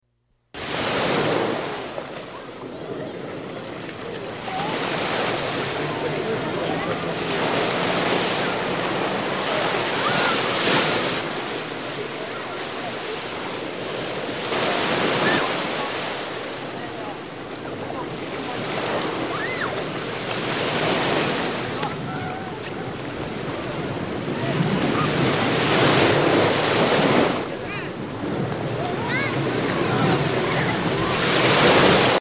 جلوه های صوتی
دانلود آهنگ سه بعدی 10 از افکت صوتی طبیعت و محیط